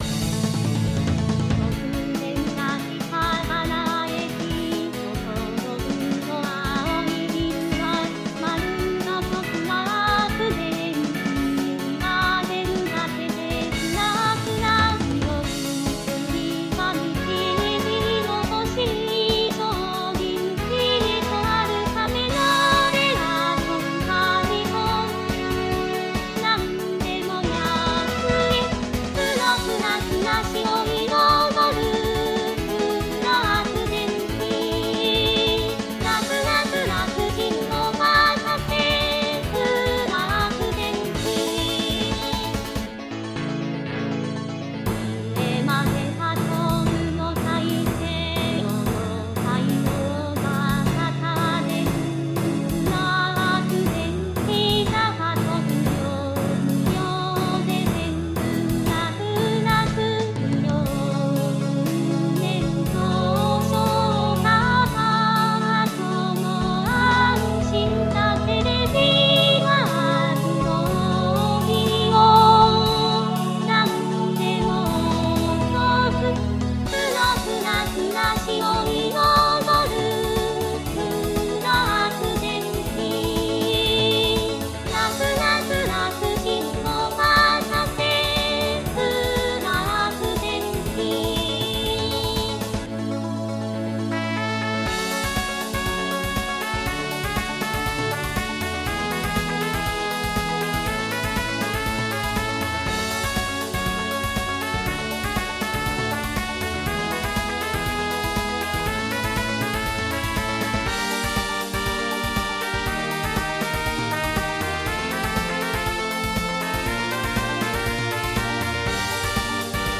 盆踊り